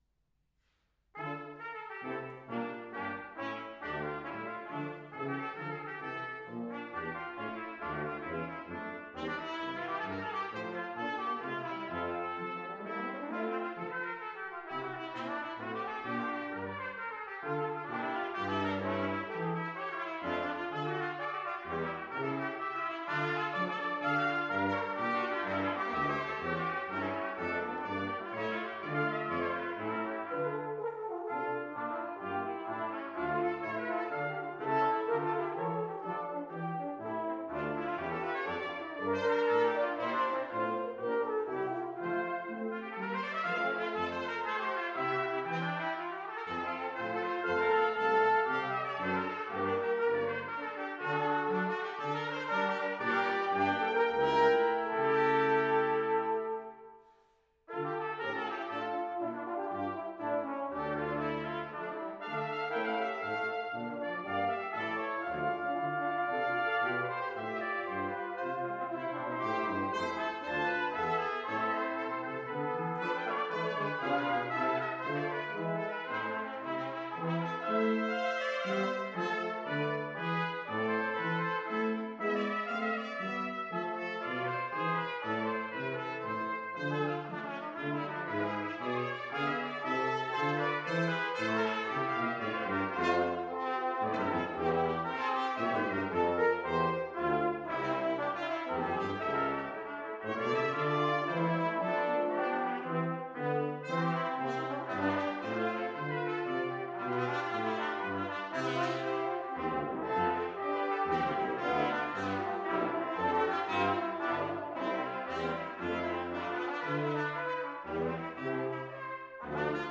Faculty Brass Quintet, Faculty Artist Recital
April 9, 2019 7:30pm All Faiths Chapel
Music, Theatre, and Dance Faculty Recitals